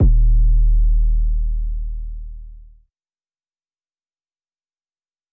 South 808.wav